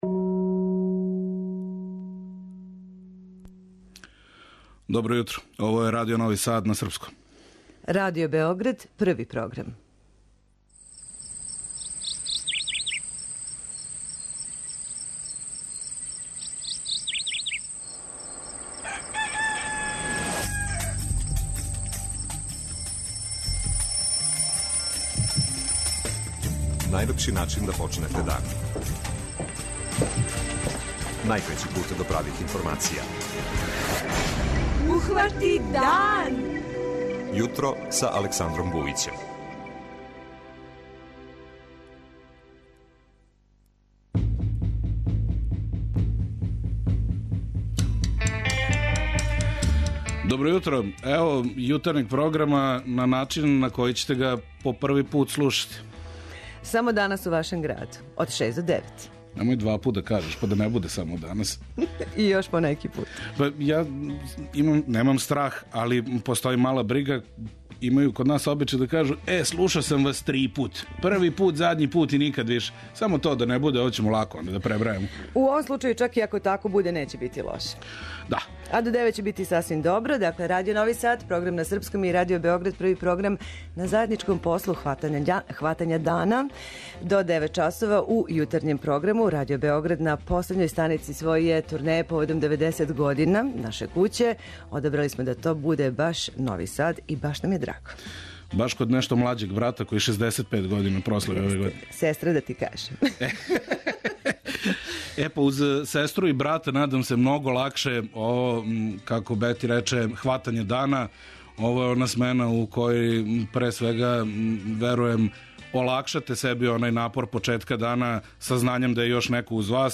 Овога јутра дан хватамо у Новом Саду, који је заиста последња станица велике турнеје Радио Београда организоване поводом прославе јубилеја наше куће - 90 година од оснивања.